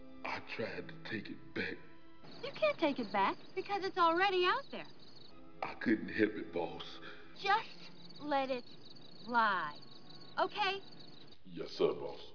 Section 2 - Mixed Conversations (3 points per movie)
For each sound in this round, we've taken one half of two different conversations from two different movies and combined them into one almost coherent conversation.